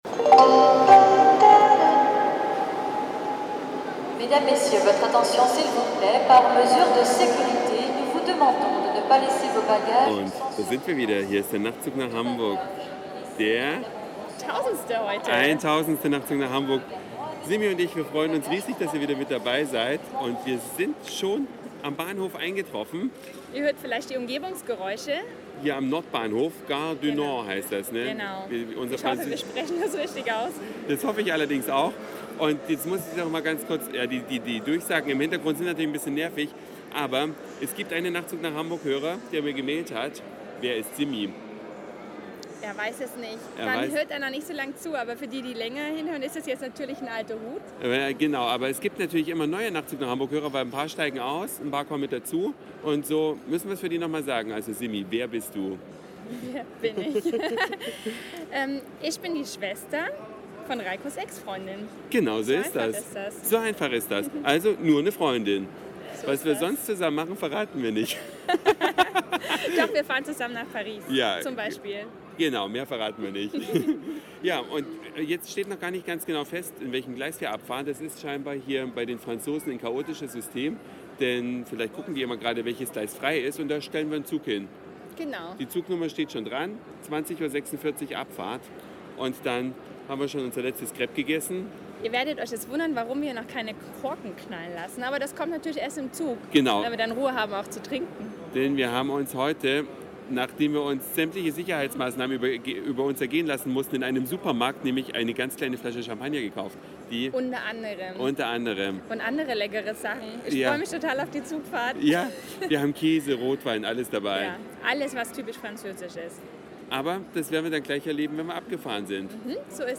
Von der Abfahrt in Paris bis zur Ankunft in Hamburg könnt Ihr uns im Schlafwagen des Zuges Nr. 243 begleiten.